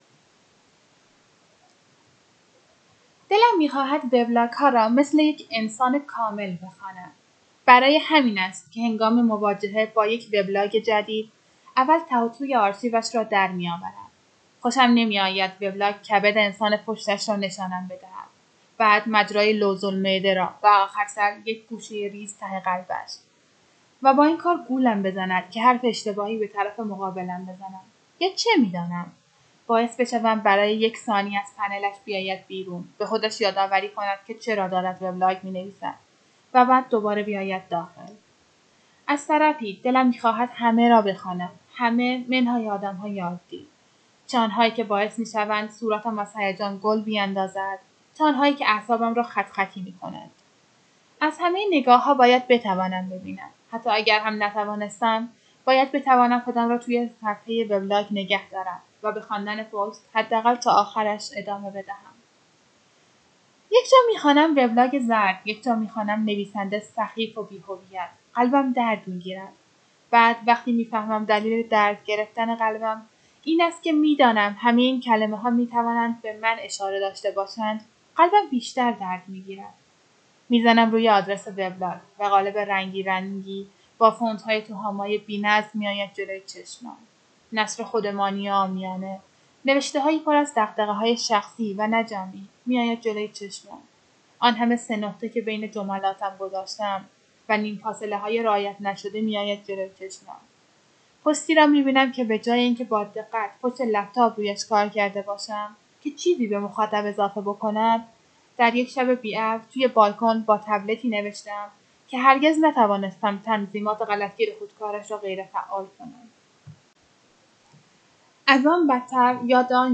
اولا هرکاری کردم نتونستم یه نفس بخونم متنش رو و دو تیکه شد.